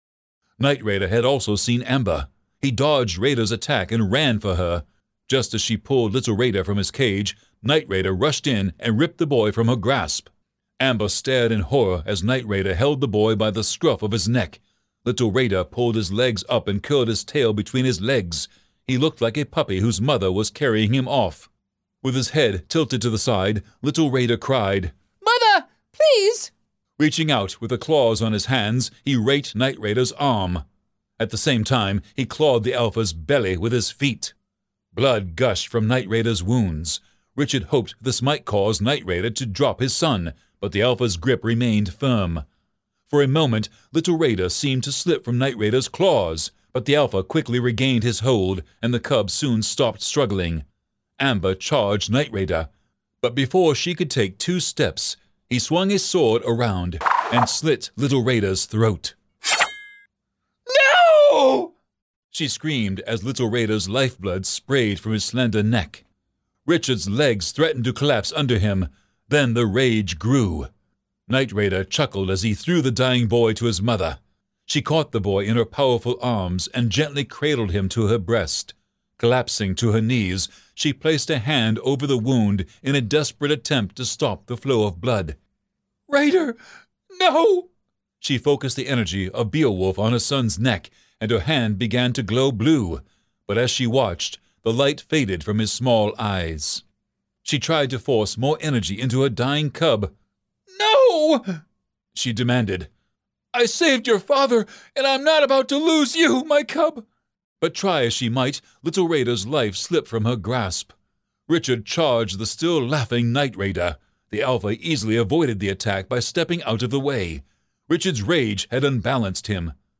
ALL FOUR AUDIOBOOKS NOW AVAILABLE IN YOUR FAVORITE STORES!